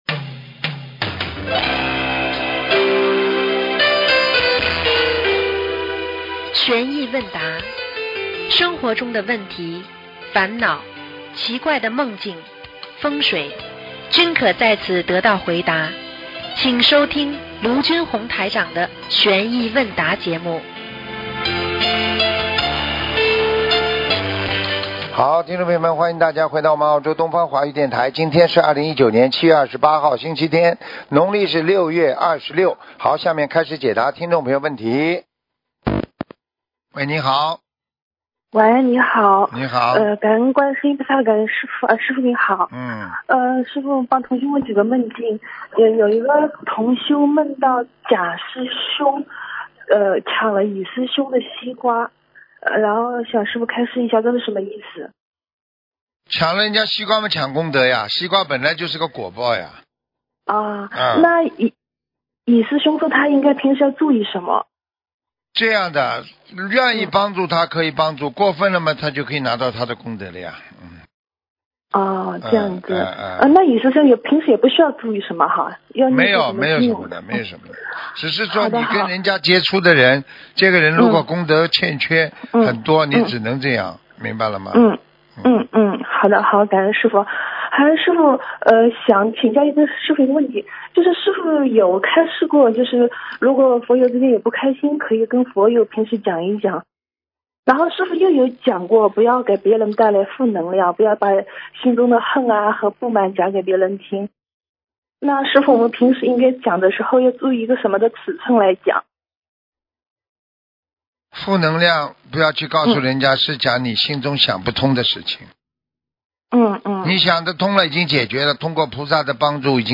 首页 >>剪辑电台音频 >> 2019年07月